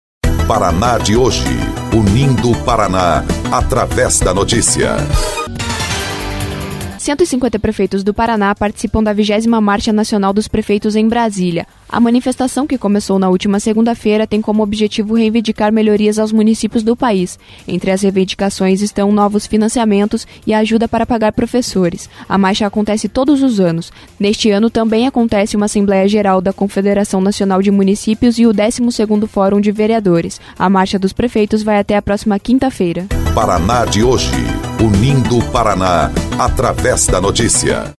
16.05 – BOLETIM – Prefeitos do Paraná participam de Marcha em Brasília